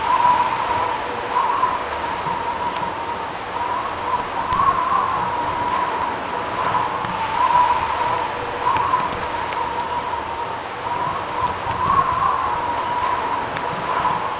highwind.wav